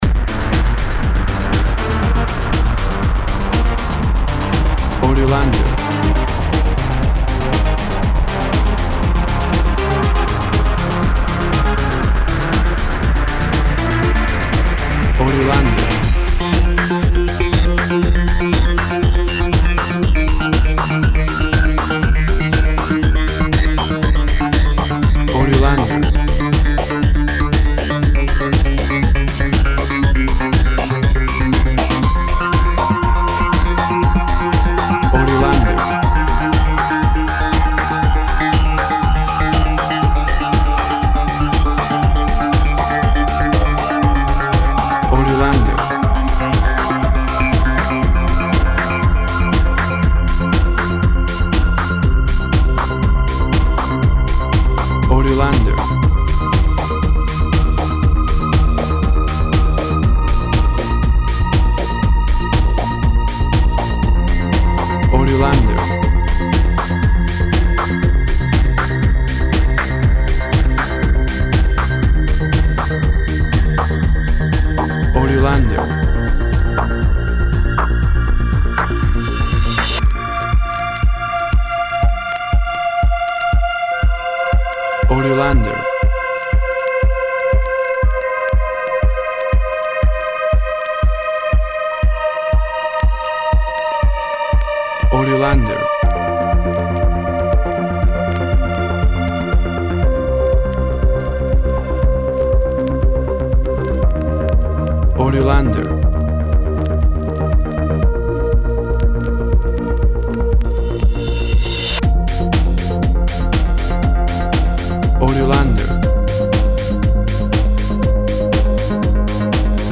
A cinematic style Synth EDM track
Tempo (BPM) 120